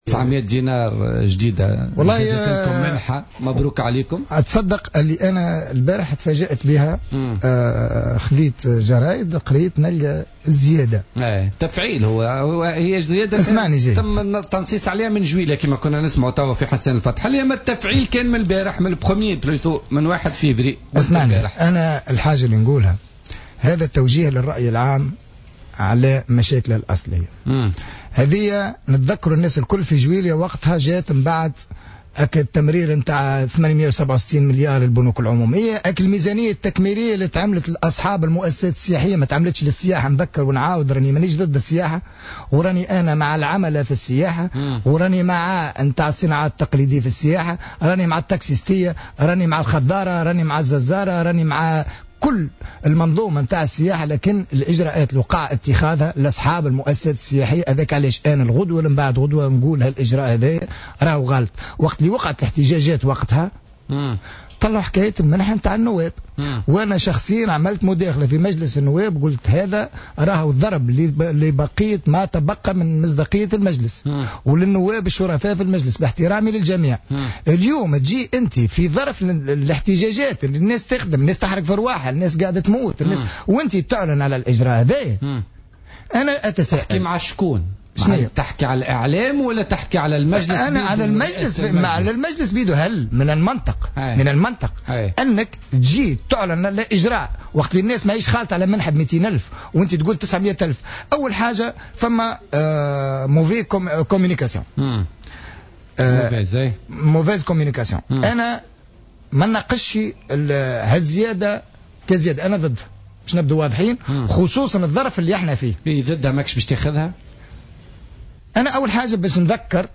وأضاف التبيني ضيف برنامج "بوليتيكا" اليوم الأربعاء أنه ضدّ هذه الزيادة،التي تأتي في ظل احتقان اجتماعي للمطالبة بالتشغيل والتنمية.